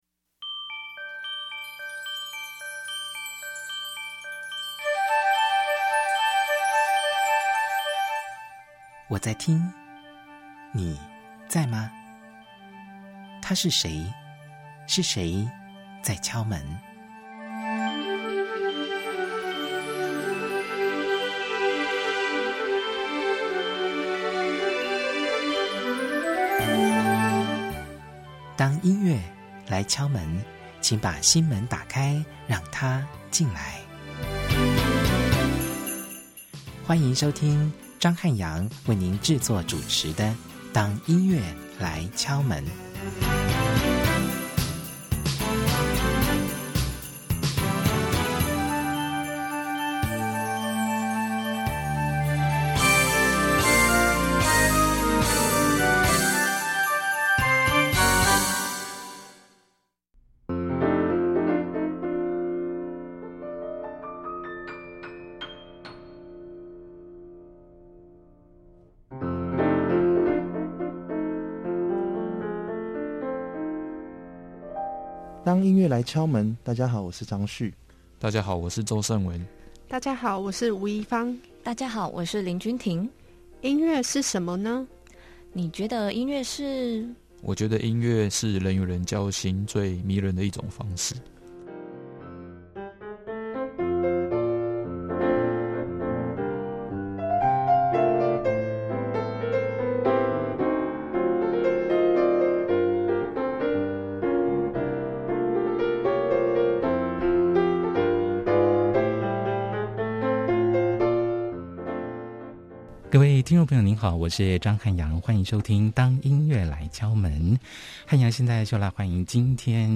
在本集節目中，無論是談到疫情期間的自處之道，或是對於音樂會曲目的樂思解讀，四位來賓都有非常精彩的分享與對話，笑語不斷，歡迎收聽。